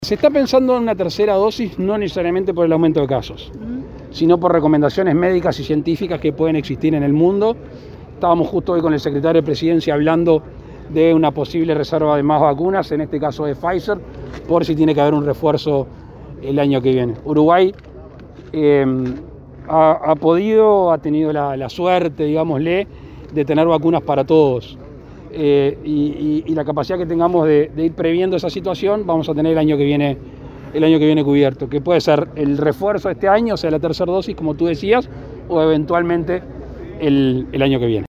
En rueda de prensa el presidente dijo  que “si todo sale bien” a mediados de julio se podría contar con 2 millones de personas vacunadas con una segunda dosis.
LACALLE-POU-tercera-dosis.mp3